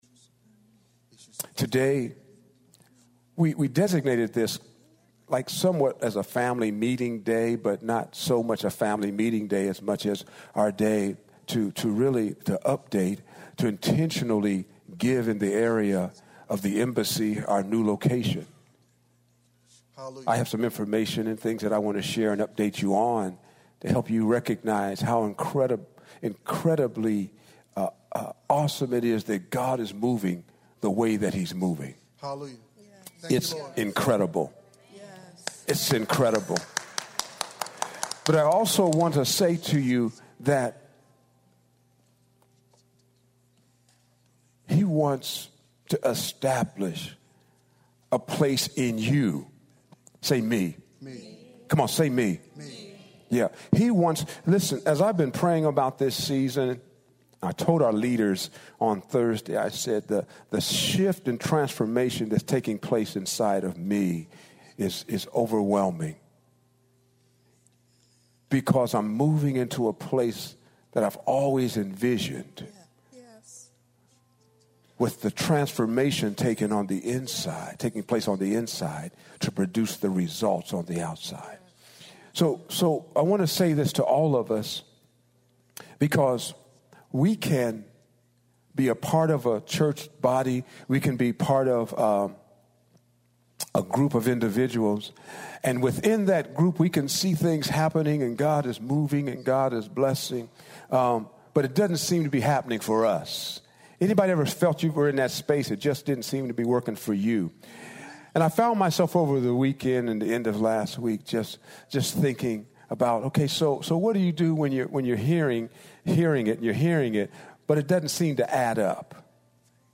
Exhortation Sunday October 14, 2018